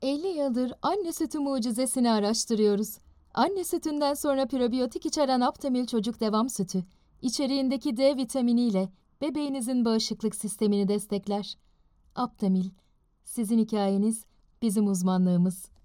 Radio & TV Commercial Voice Overs Talent, Artists & Actors
Yng Adult (18-29) | Adult (30-50)